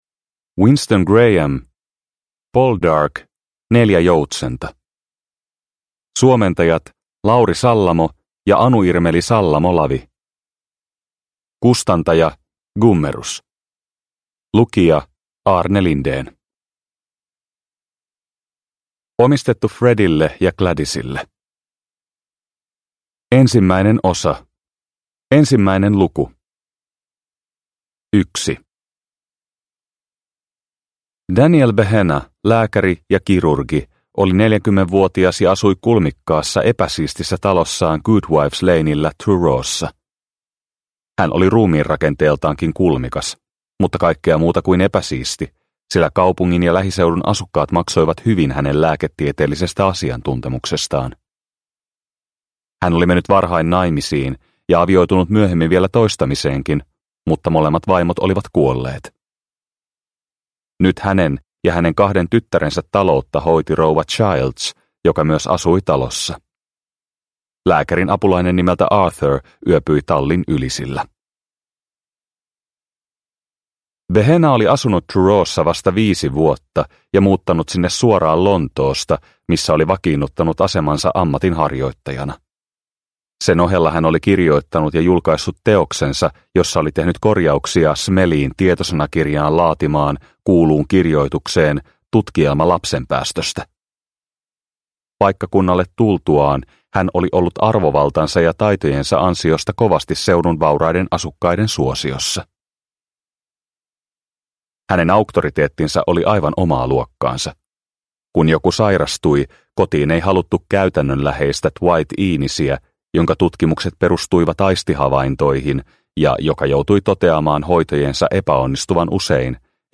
Poldark - Neljä joutsenta – Ljudbok – Laddas ner